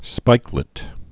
(spīklĭt)